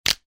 На этой странице собраны звуки перелома костей – от резких щелчков до глухих тресков.
Звук хруста шеи